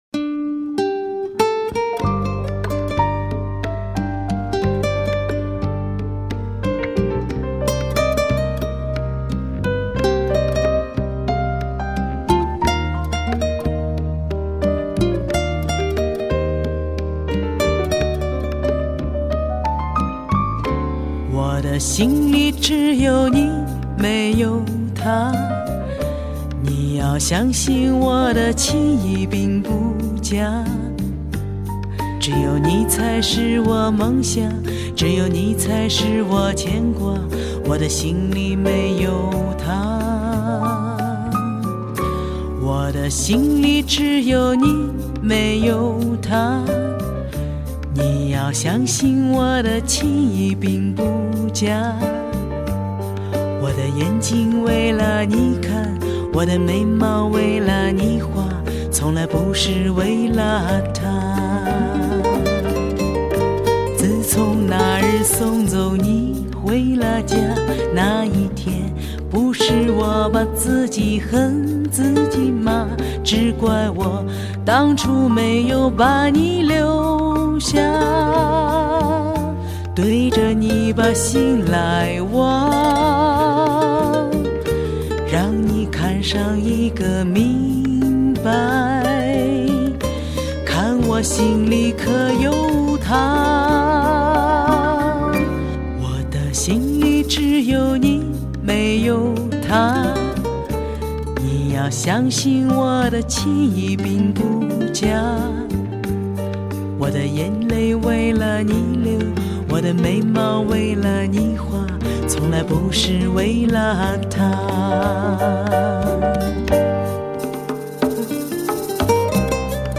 女中低音歌喉